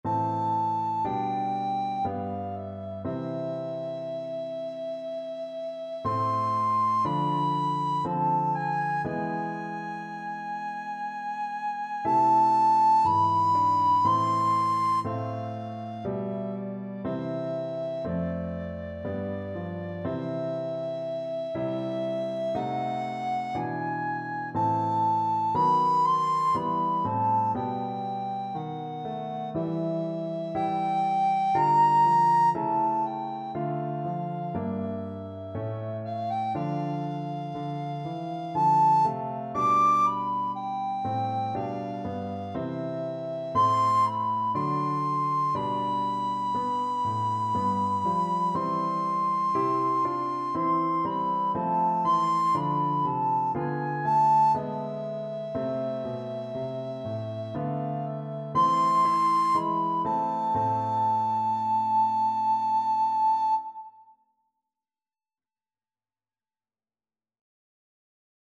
Free Sheet music for Soprano (Descant) Recorder
A minor (Sounding Pitch) (View more A minor Music for Recorder )
Lento
3/4 (View more 3/4 Music)
D6-D7
Classical (View more Classical Recorder Music)